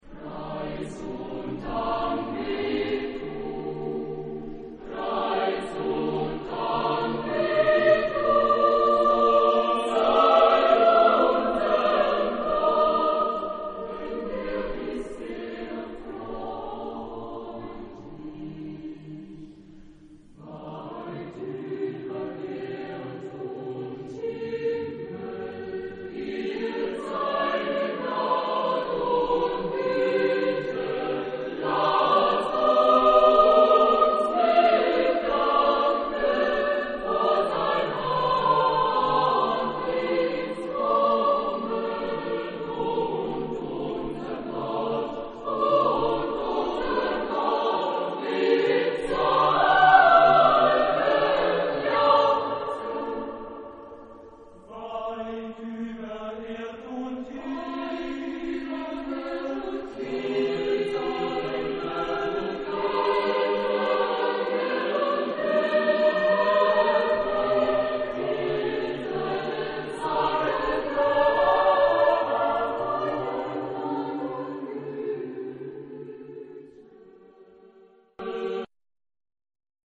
Genre-Style-Form: Sacred ; Romantic
Mood of the piece: majestic ; contemplative
Type of Choir: SATB  (4 mixed voices )
Tonality: D major
Consultable under : Romantique Sacré Acappella